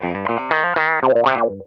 ITCH RIFF 3.wav